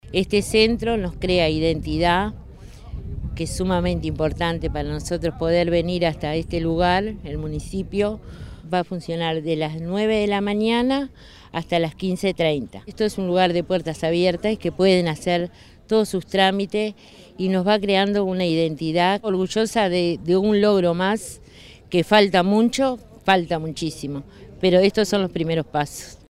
El Gobierno de Canelones y el Correo Uruguayo realizaron la inauguración del Centro de Cercanía de 18 de Mayo, ubicado en la calle Av. Maestro Julio Castro esquina Solís.